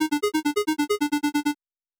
Hero_Dies.wav